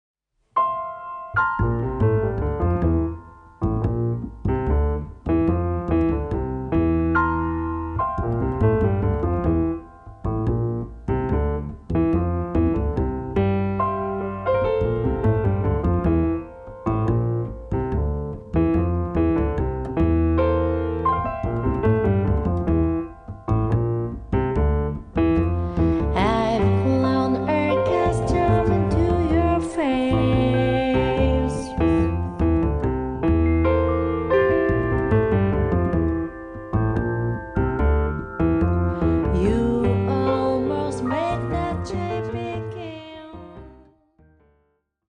Recorded on Dec. 22nd and 23rd, 2024 at Studio Dede, Tokyo